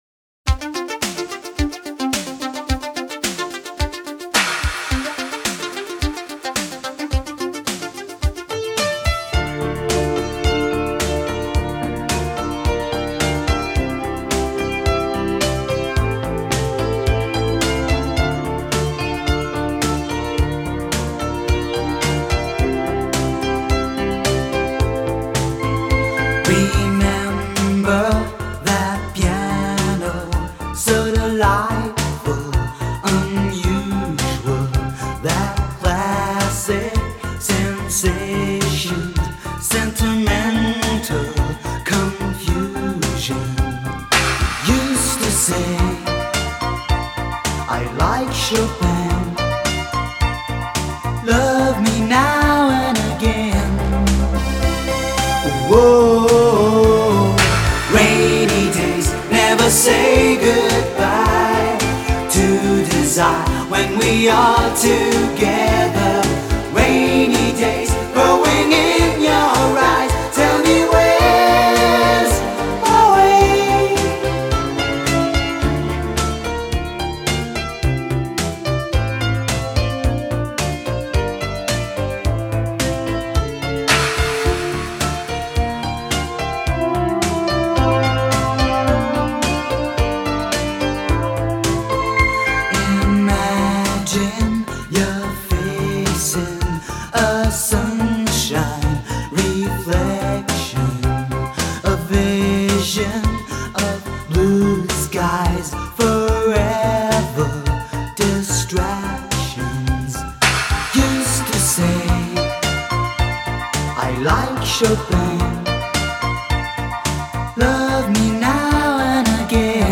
过五张专辑和数张单曲，他的曲风较偏于欧式抒情
邦致敬的名作，使用了不少模拟钢琴效果的电子合声，
到后来电子钢琴的伴奏简直如同肖邦再次横空出世，